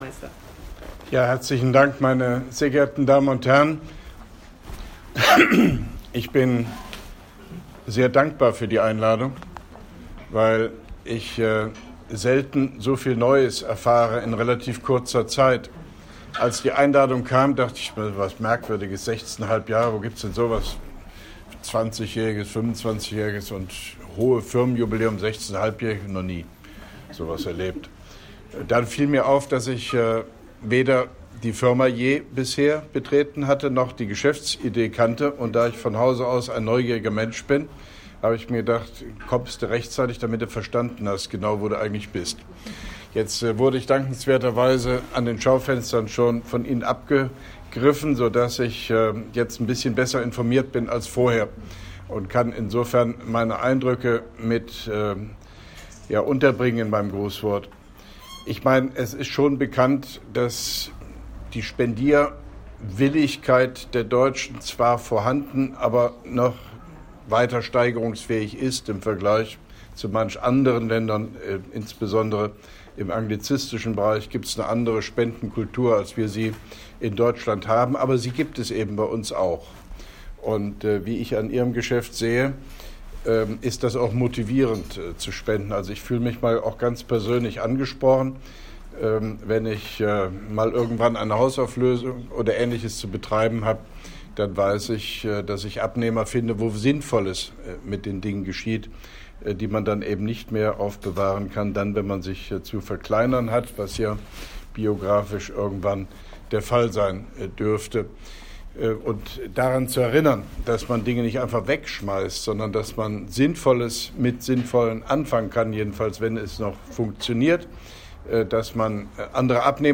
Grußwort von OB Hofmann-Göttig bei der Jubiläumsfeier anlässlich des 16-jährigen Bestehens von “Oxfam Deutschland”, Koblenz 28.09.2017